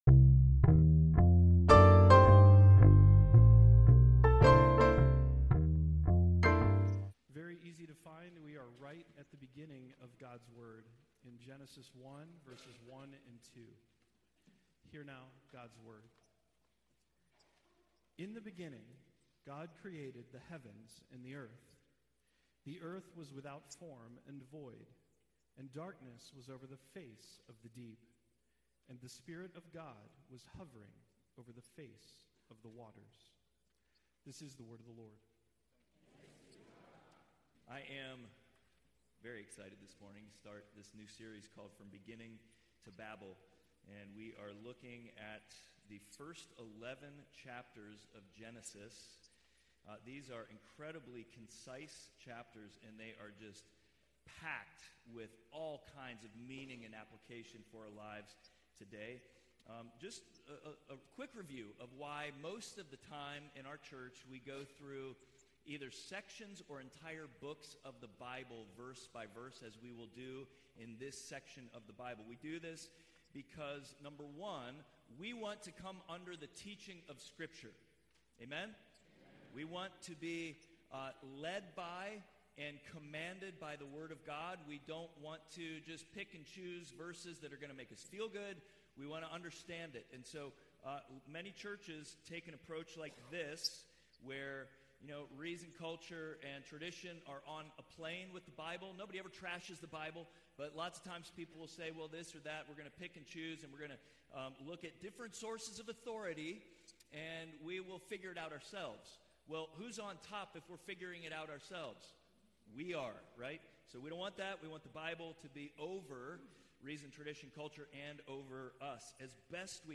Passage: Genesis 1: 1-2 Service Type: Sunday Worship